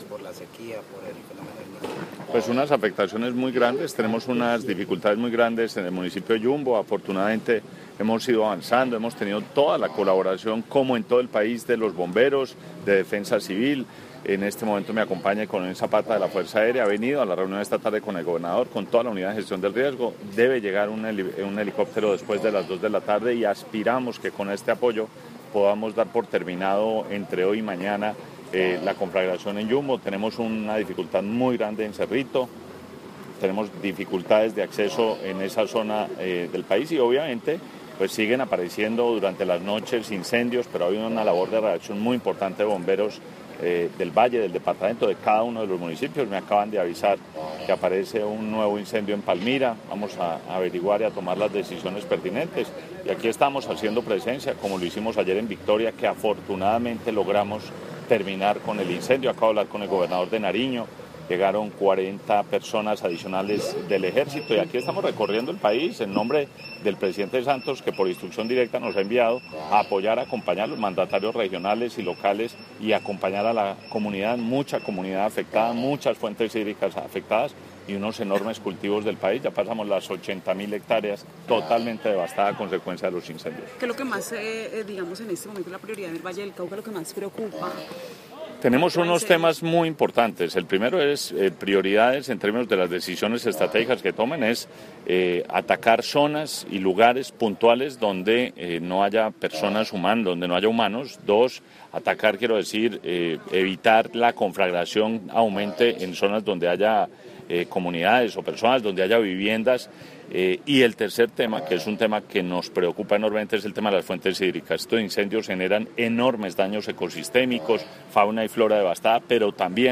Declaraciones del Ministro de Ambiente y Desarrollo Sostenible, Gabriel Vallejo López